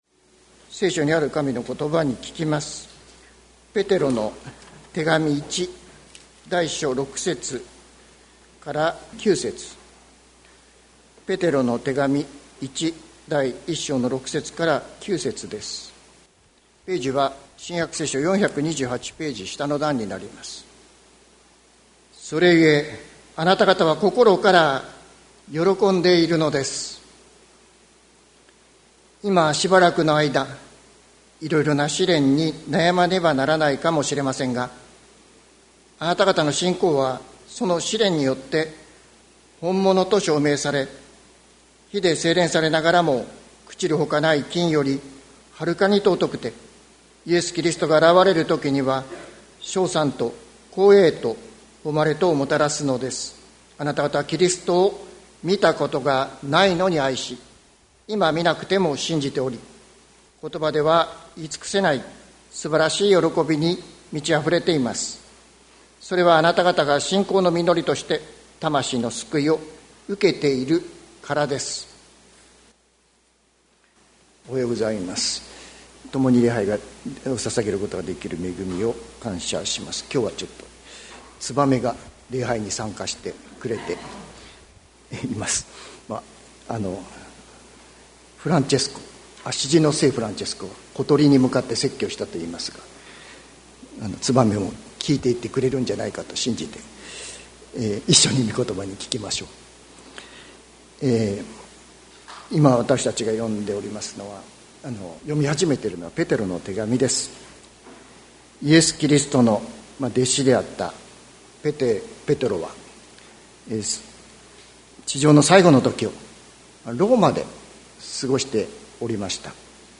2024年08月11日朝の礼拝「見なくても愛し、見なくても信じ」関キリスト教会
説教アーカイブ。